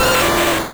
Cri de Magmar dans Pokémon Rouge et Bleu.